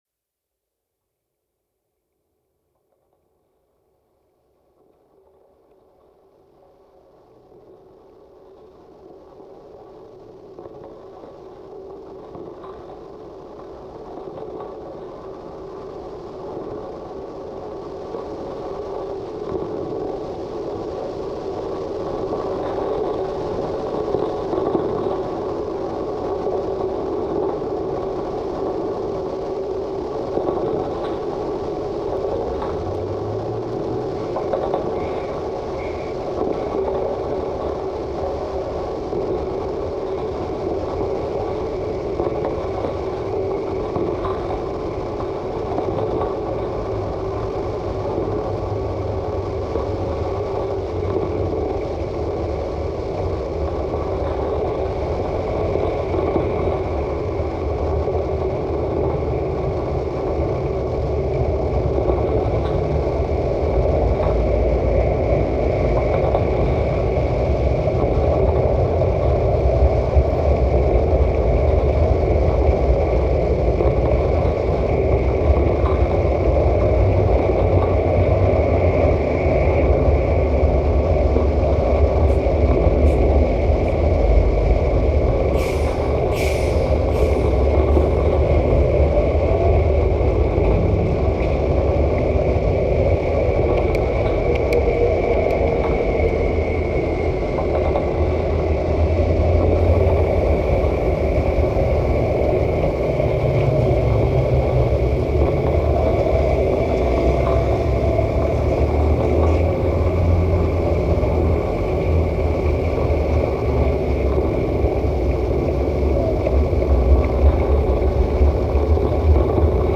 rollicking 15-minute misadventure in drone and noise
A full-on noise debacle